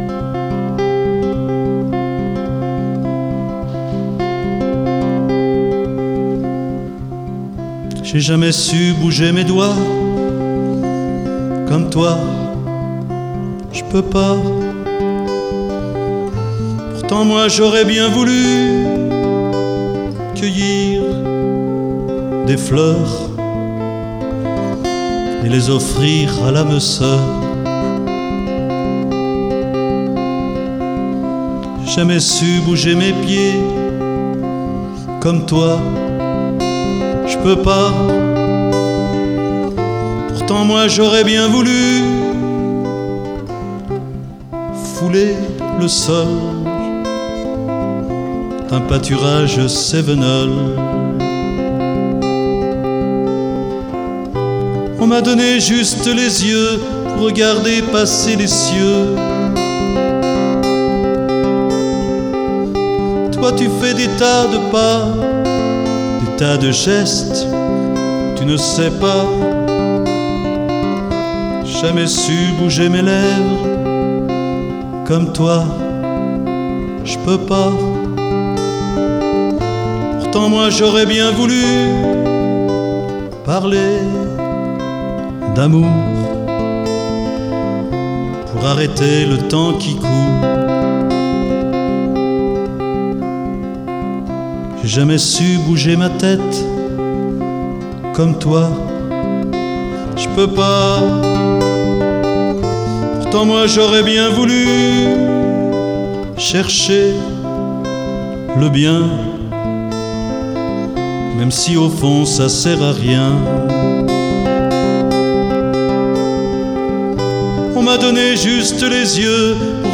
chant et guitare
contrebasse
Une chanson intimiste, qui aborde la question du handicap.